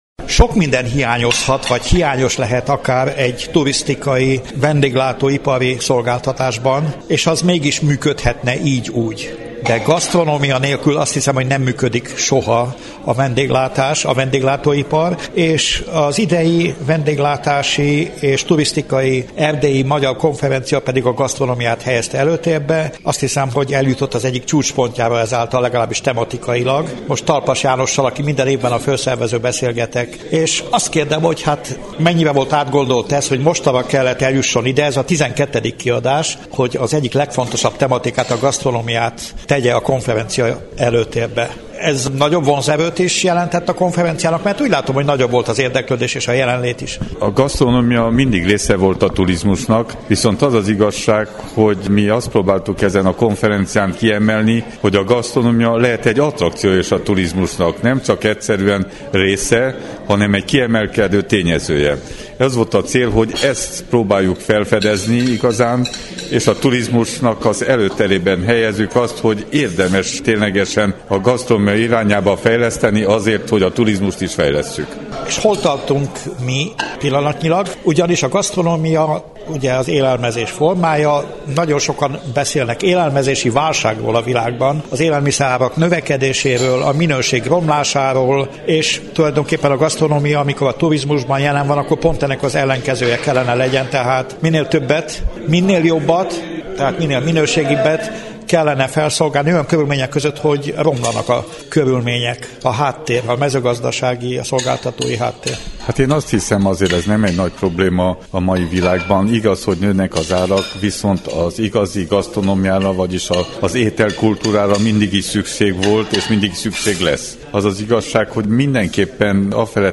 Végül az az interjú következik